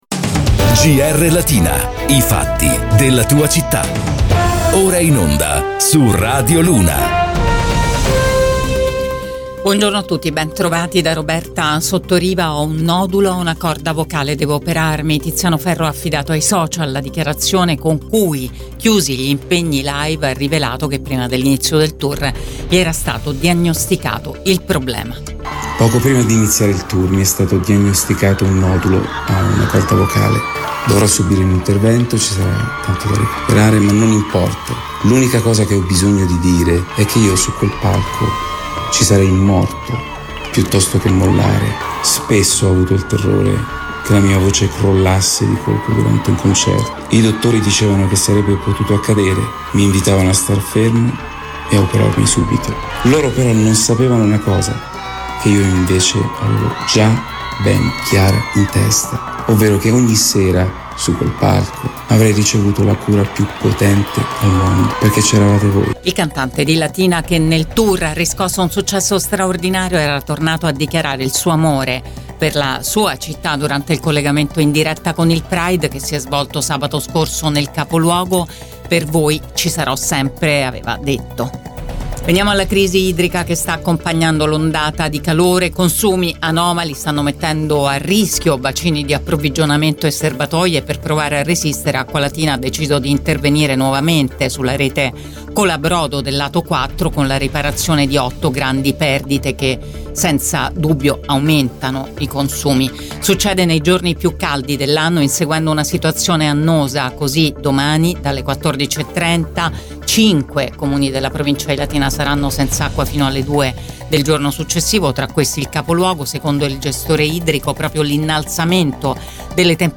LATINA – Qui puoi ascoltare il podcast di GR Latina in onda su Radio Immagine, Radio Latina e Radio Luna